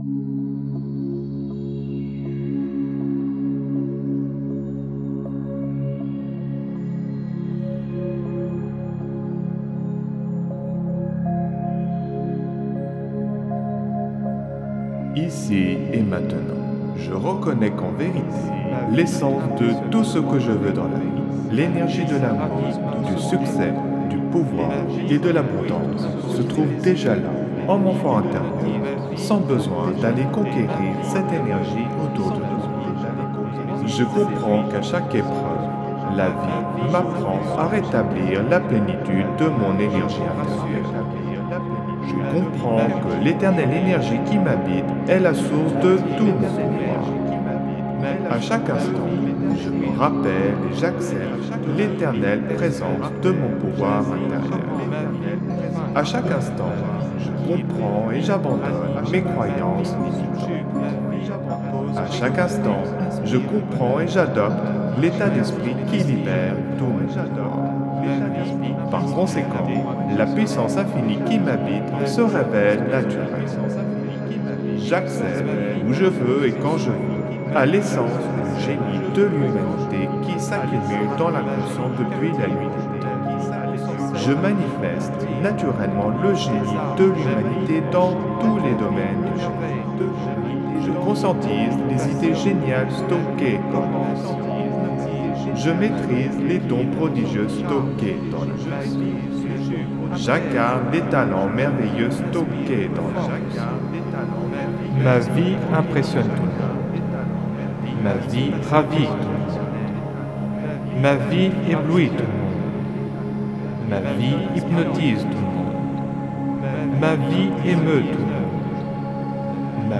(Version ÉCHO-GUIDÉE)
Alliage ingénieux de sons et fréquences curatives, très bénéfiques pour le cerveau.
Pures ondes gamma intenses 69,06 Hz de qualité supérieure. Puissant effet 3D subliminal écho-guidé.
SAMPLE-Super-influenceur-echo.mp3